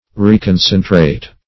Search Result for " reconcentrate" : The Collaborative International Dictionary of English v.0.48: Reconcentrate \Re`con*cen"trate\, v. t. & i. To concentrate again; to concentrate thoroughly.